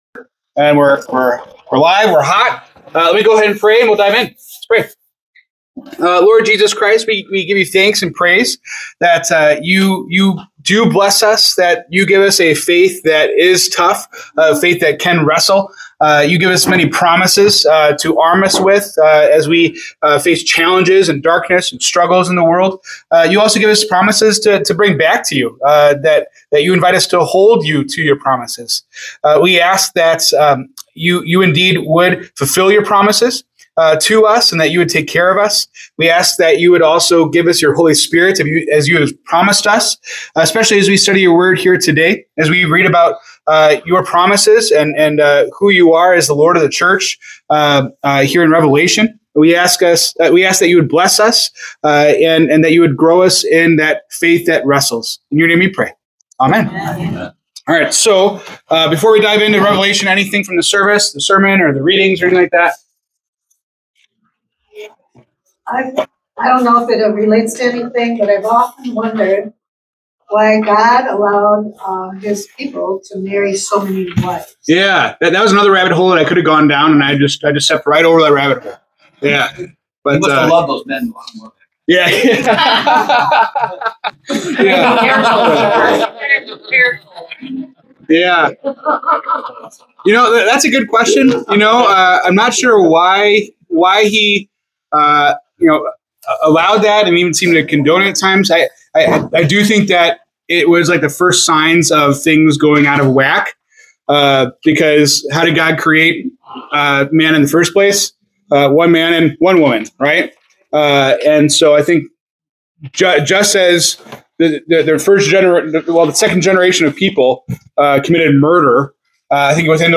October 19, 2025 Bible Study
Discussion on Revelation 2:8-11.